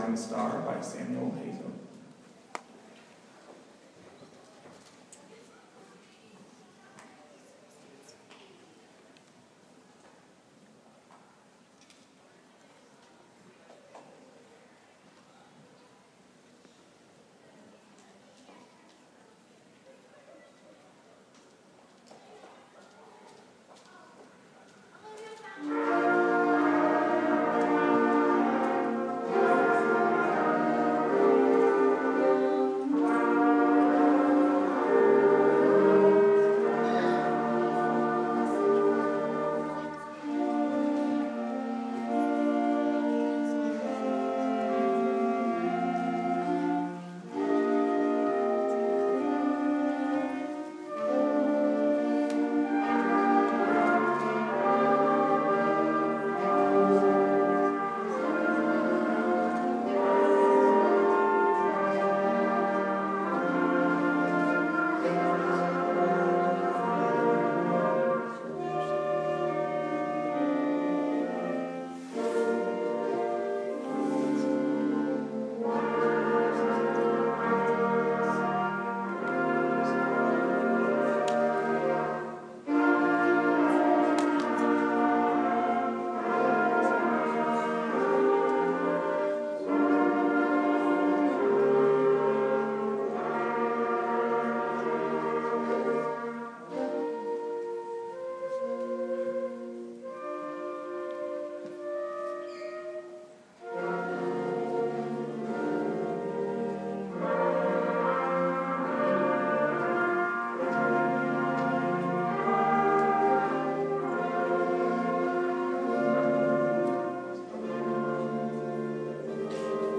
6th grade cadet band